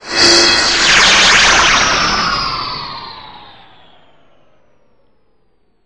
teleport1.wav